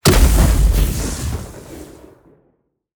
mixkit-fuel-explosion-1705.wav